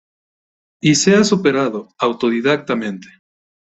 su‧pe‧ra‧do
/supeˈɾado/